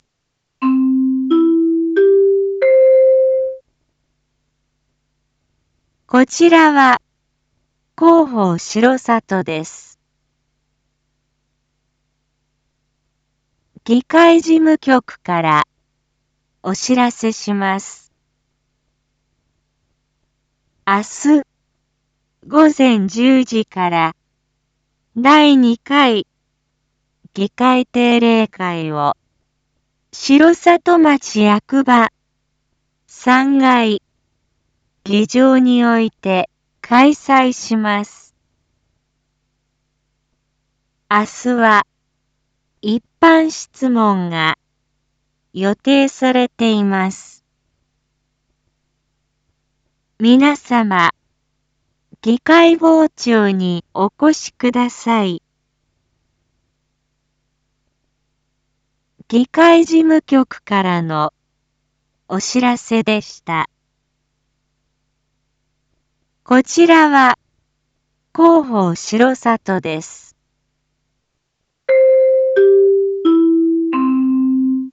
一般放送情報
Back Home 一般放送情報 音声放送 再生 一般放送情報 登録日時：2023-06-06 19:01:16 タイトル：6/6夜 議会 インフォメーション：こちらは広報しろさとです。